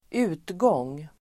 Uttal: [²'u:tgång:]